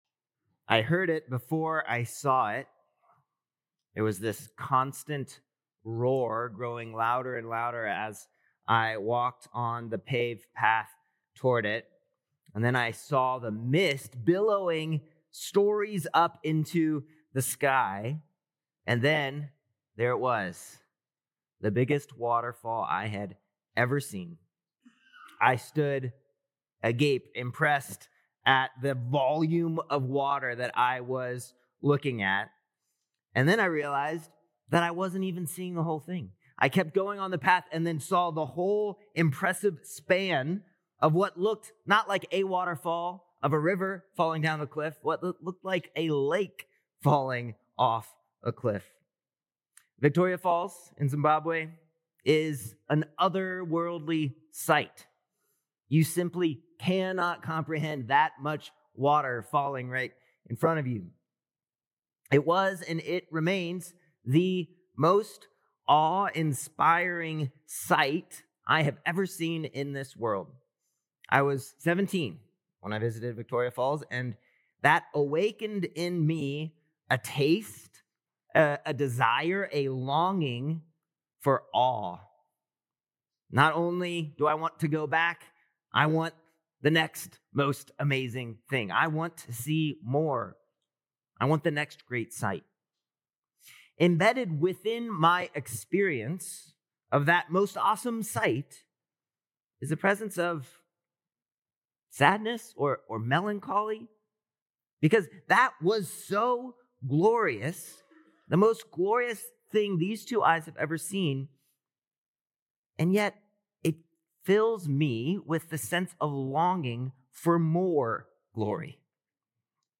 November 16th Sermon